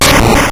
destroy.wav